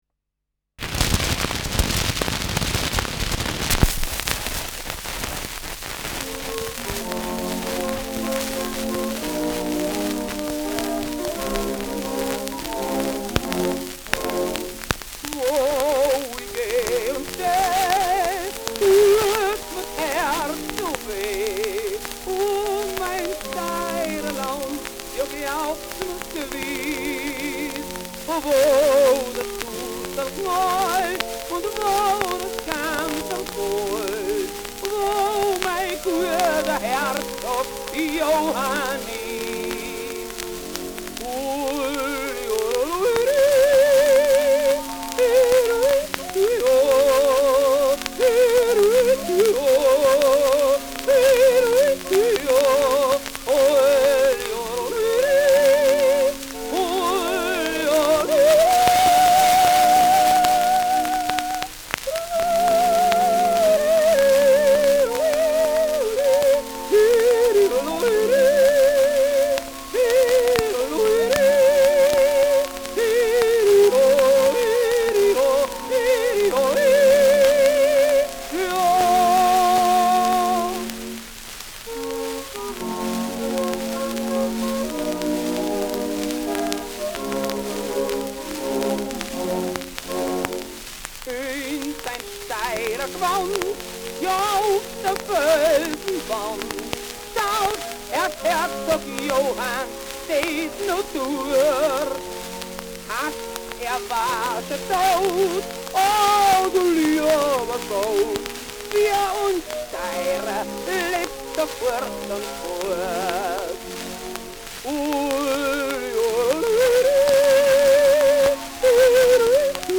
Schellackplatte
Abgespielt : Nadelgeräusch : Zu Beginn stärkeres Knacken : Erhöhtes Grundrauschen : Teils verzerrt : Durchgehend leichtes Knacken
[unbekanntes Ensemble] (Interpretation)
Etikett: Favorite Record : Sm : Austrian Jodeling song with Orchestra : Erzherzog-Johann-Jodler, von Th.
Jodler mit Orchester